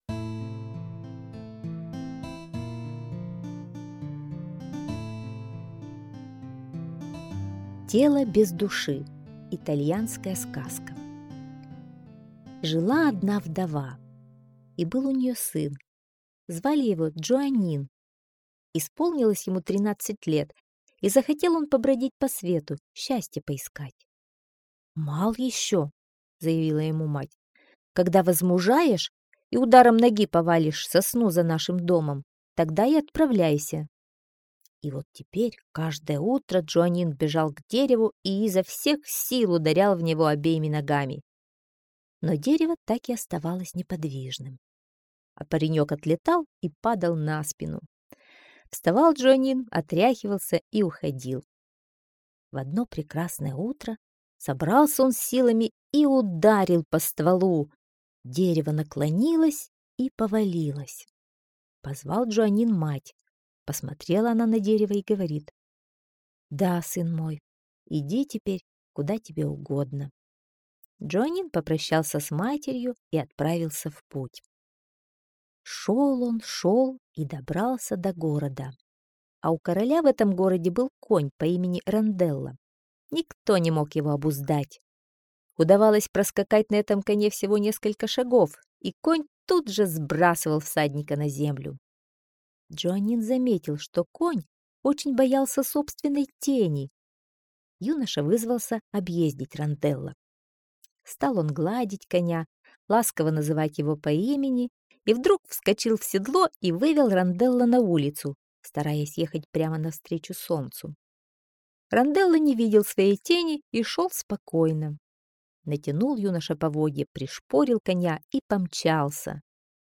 Тело-без-души - итальянская аудиосказка - слушать онлайн